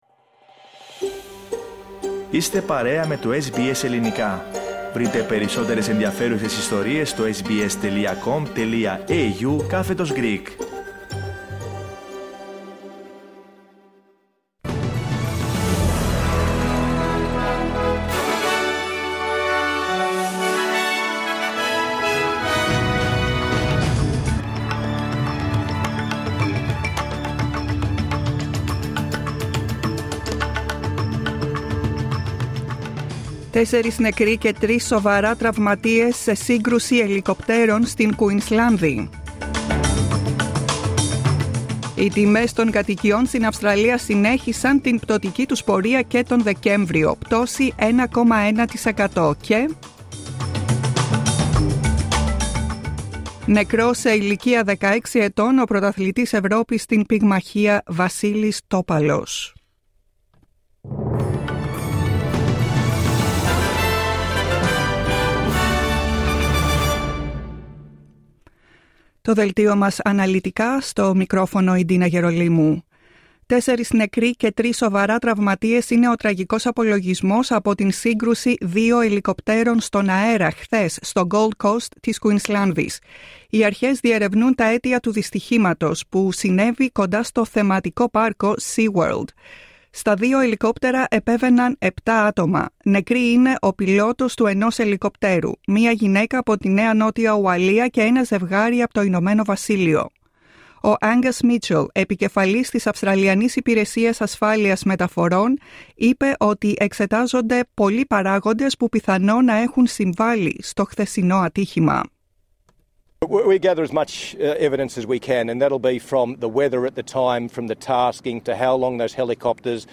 News bulletin, 03.01.23